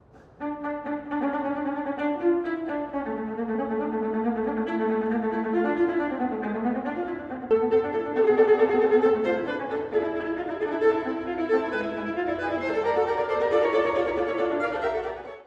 ↑古い録音のため聴きづらいかもしれません！（以下同様）
夜の山に魔女、妖怪、妖精たちが集まって踊るようです。